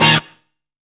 POW STAB.wav